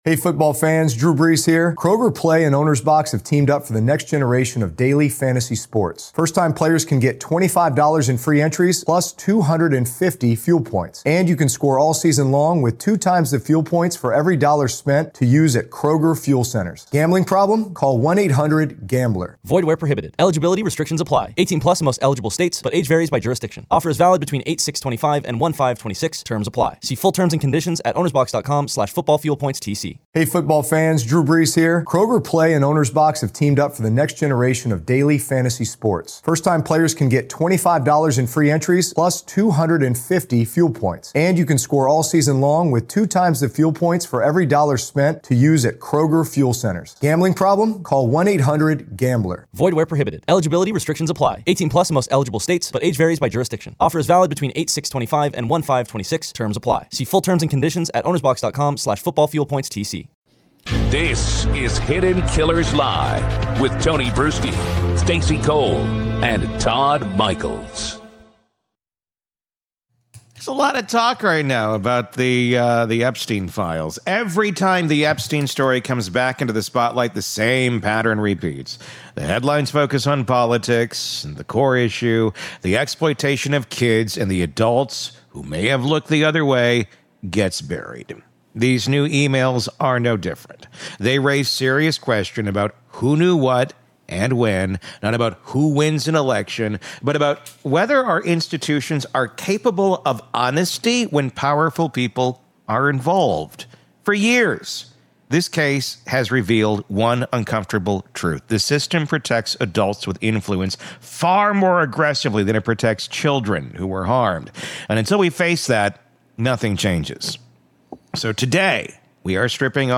True Crime Today | Daily True Crime News & Interviews / Epstein Emails Exposed — The Secrets Powerful Men Don’t Want Out